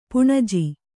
♪ puṇaji